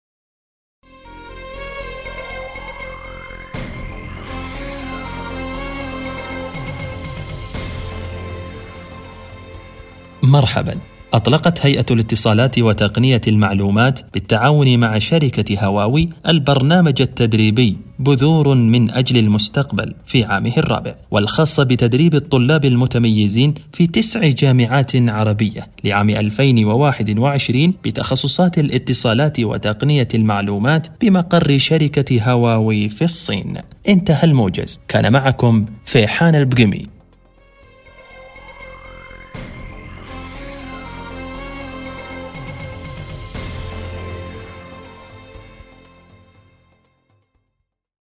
تعليق صوتي إخباري
تعليق صوتي لنص إخباري